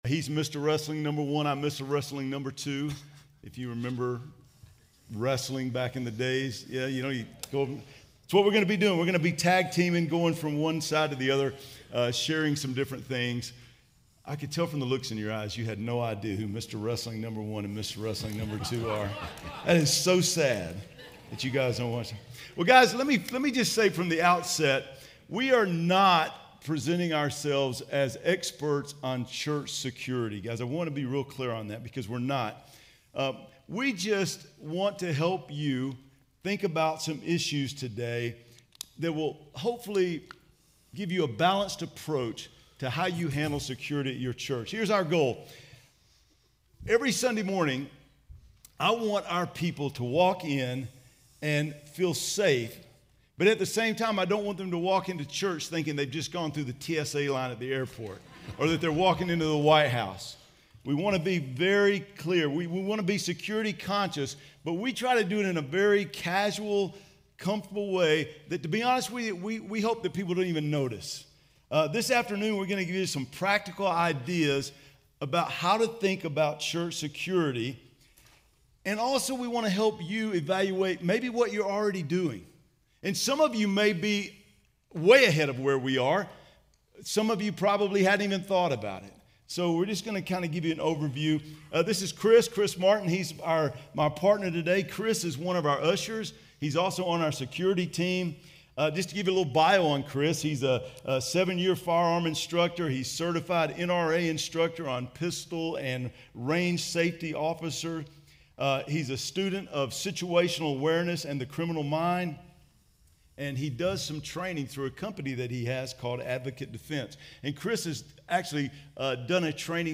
2023 DSPC Conference: Pastors & Leaders